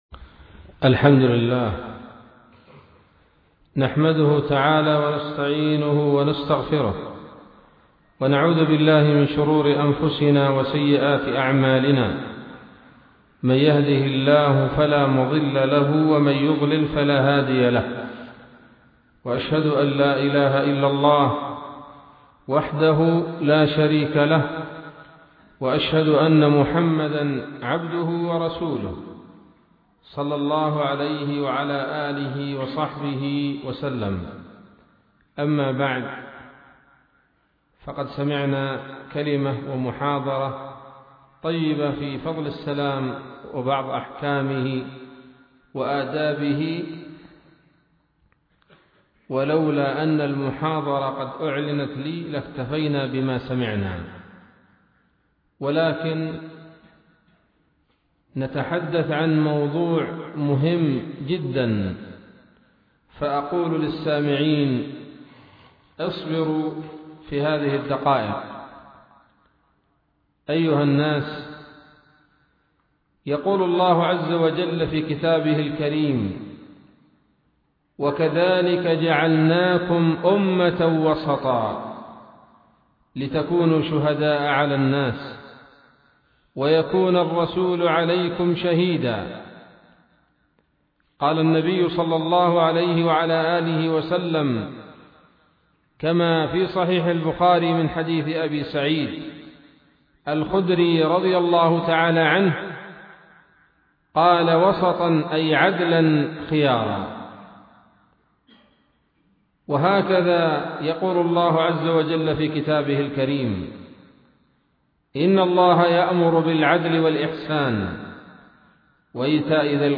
محاضرة بعنوان :((التحذير من الغلو